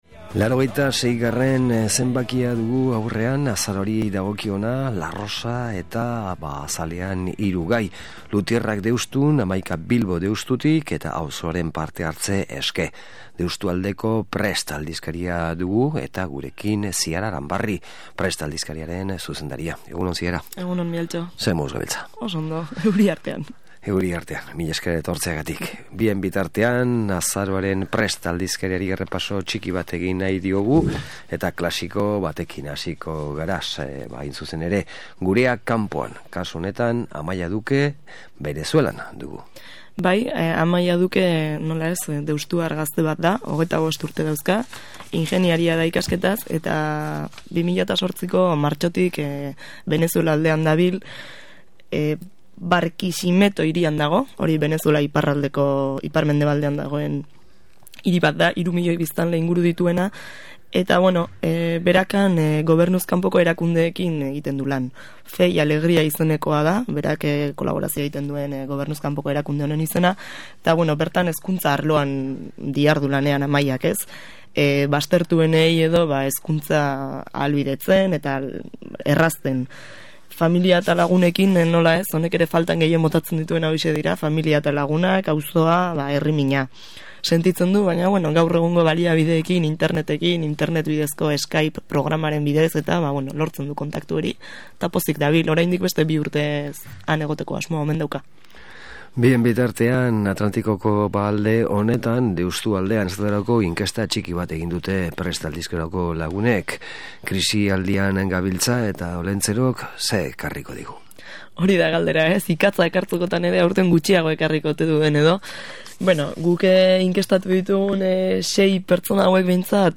solasaldia
Elkarrizketa hau deskaegatzeko klikatu HEMEN.